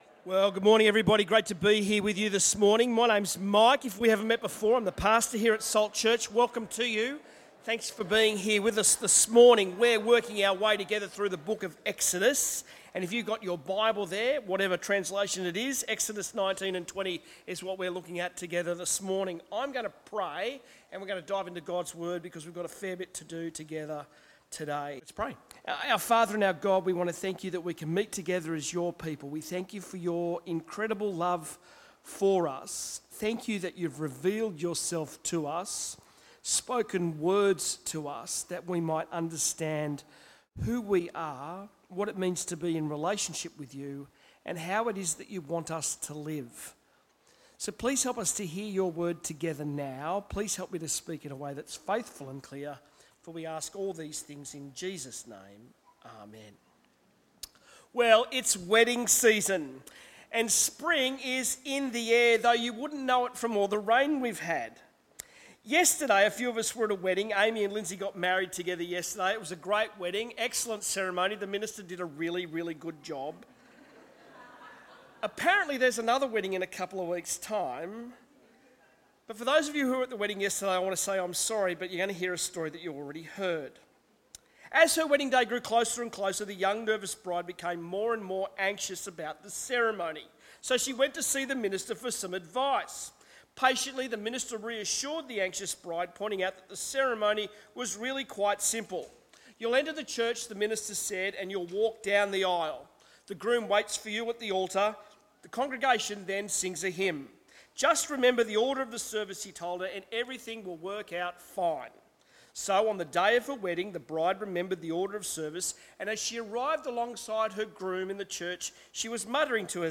Sermons
Listen to our sermons from Sunday here